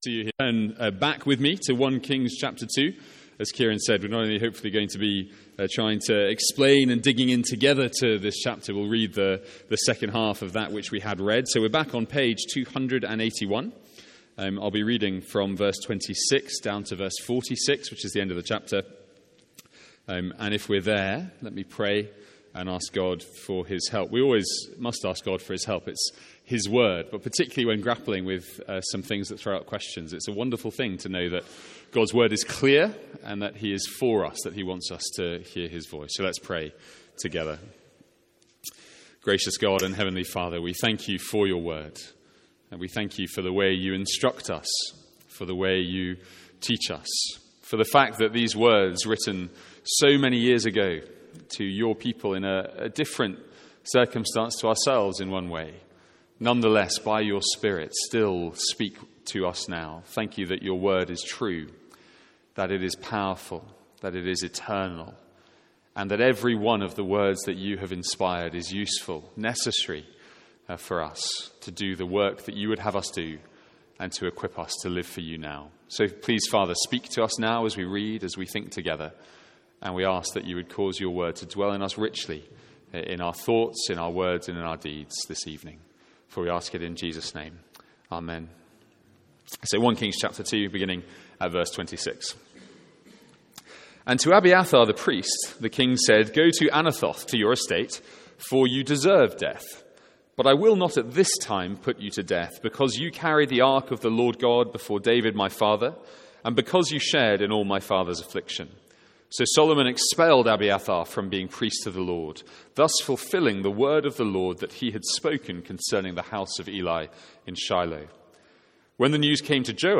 Sermons | St Andrews Free Church
From our evening series in 1 Kings.